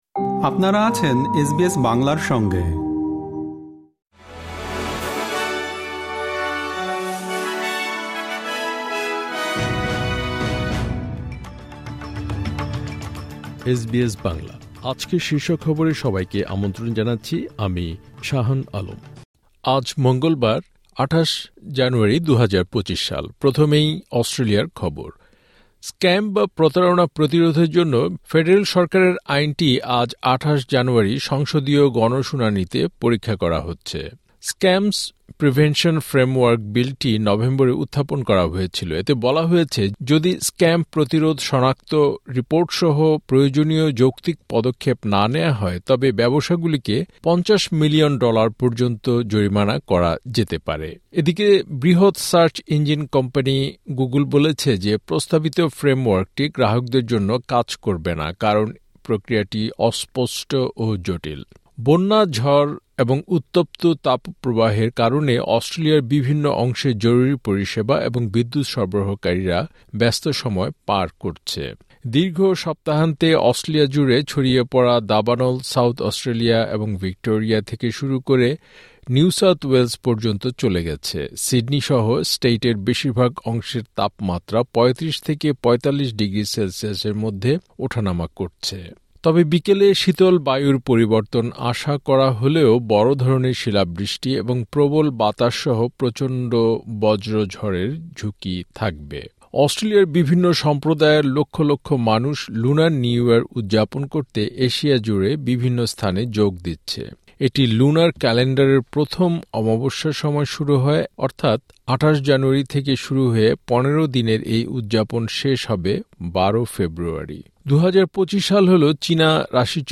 এসবিএস বাংলা শীর্ষ খবর: ২৮ জানুয়ারি, ২০২৫